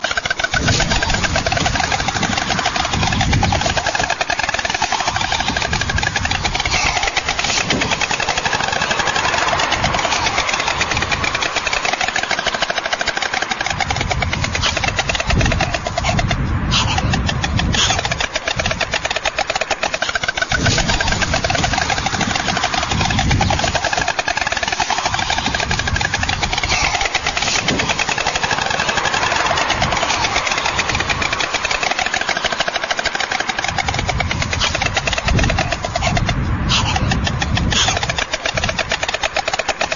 Das Klappern der Störche
Der Weiße Storch Lateinischer Name: Ciconia ciconia
Stoerche_klappern.mp3